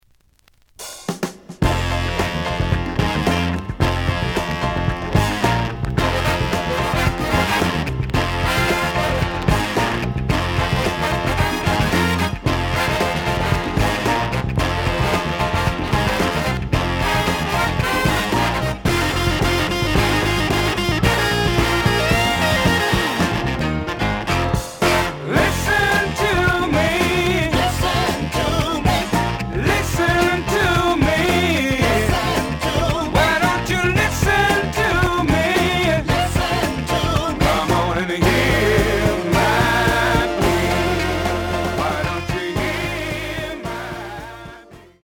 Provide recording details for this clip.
The audio sample is recorded from the actual item. B side plays good.)